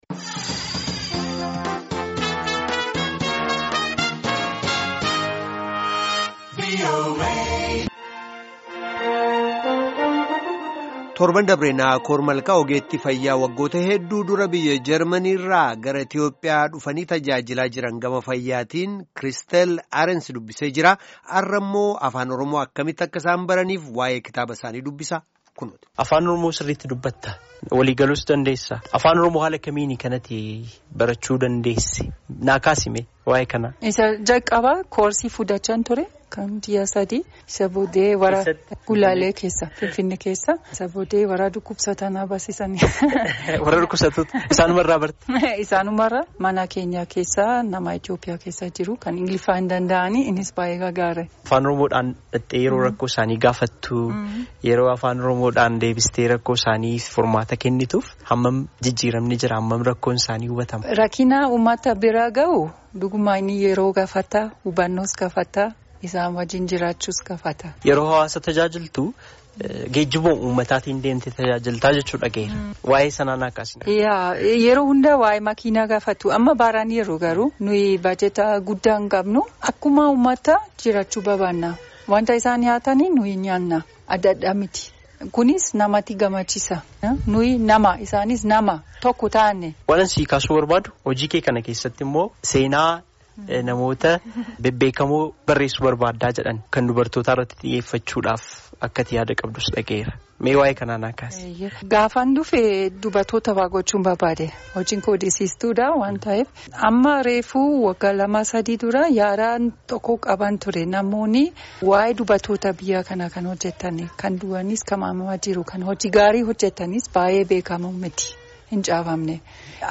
Gaaffii fi deebii Afaan Oromootiin waliin geggeessine :